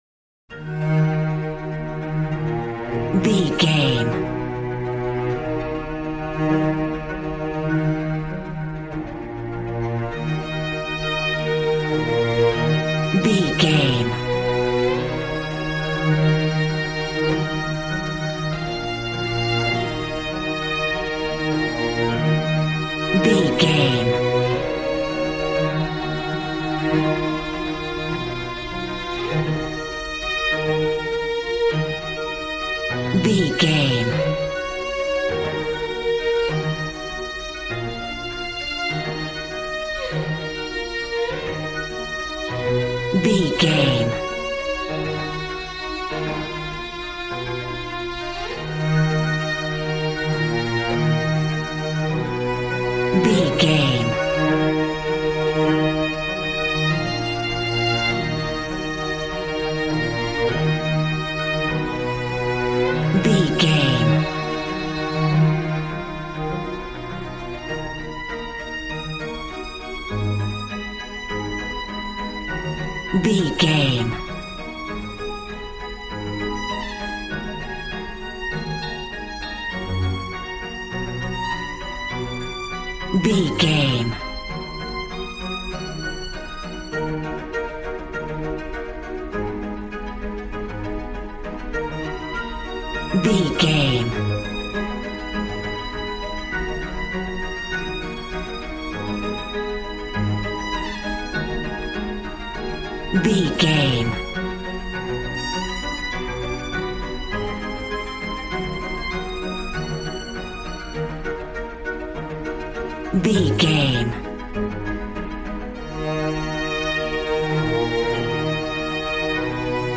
Modern film strings for romantic love themes.
Regal and romantic, a classy piece of classical music.
Aeolian/Minor
E♭
regal
cello
violin
brass